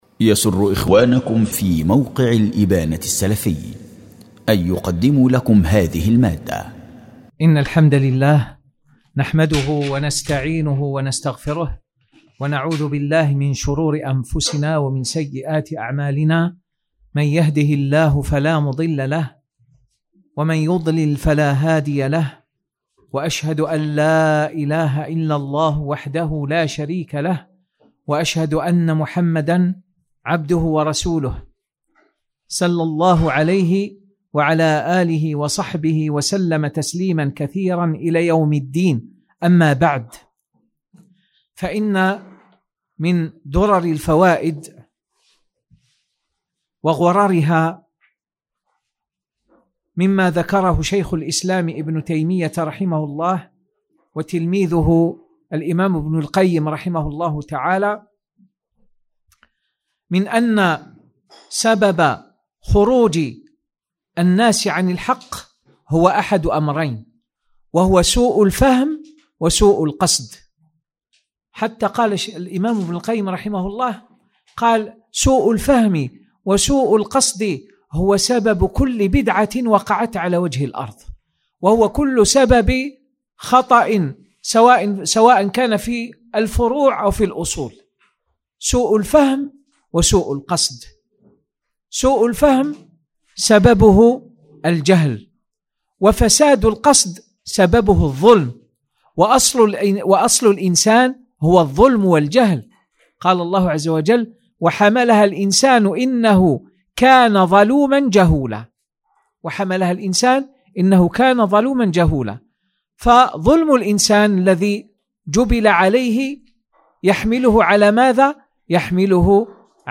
🔹بحي الحياة - جسر قسنطينة
دروس ومحاضرات